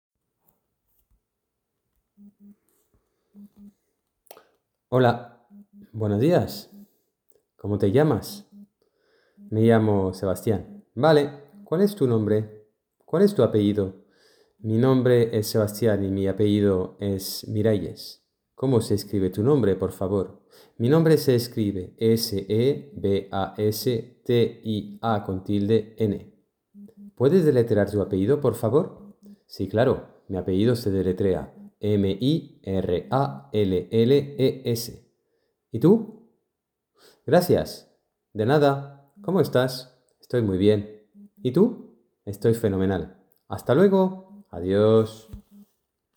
DIÁLOGO: